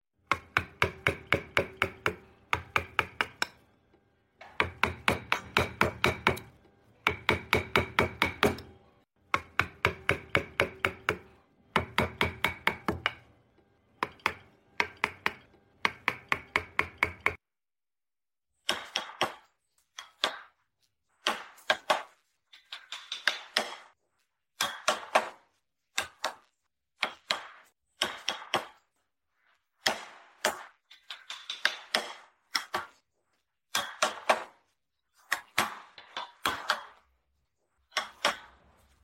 Tiếng Đục gỗ, Trạm, Chạm khắc gỗ… thủ công bằng tay
Thể loại: Tiếng động
Description: Âm thanh đục gỗ, tiếng trạm khắc, chạm khắc thủ công vang lên khô giòn, lặp nhịp, khi mũi đục chạm vào thớ gỗ tạo nên tiếng “cốc cốc”, “cạch cạch” chân thực.
Âm vang mộc mạc, rõ ràng, gợi không khí xưởng mộc, nơi bàn tay nghệ nhân kiên nhẫn tỉa từng đường nét.
tieng-duc-go-tram-cham-khac-go-thu-cong-bang-tay-www_tiengdong_com.mp3